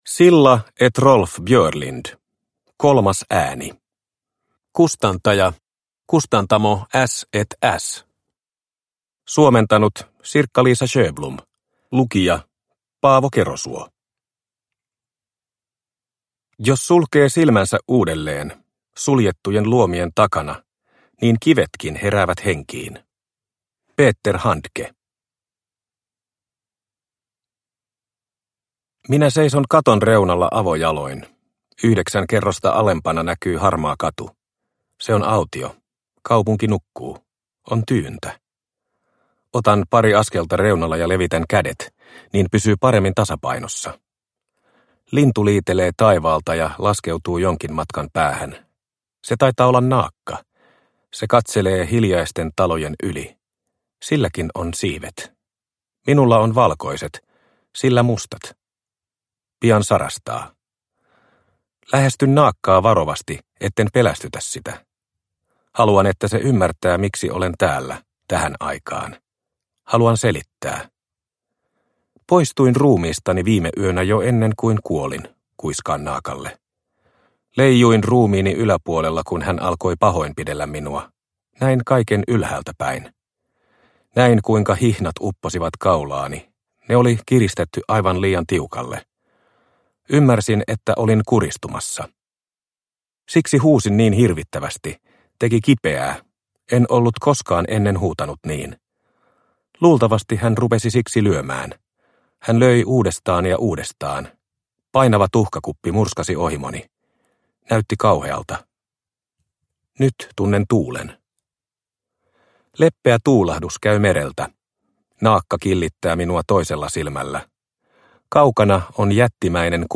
Kolmas ääni – Ljudbok – Laddas ner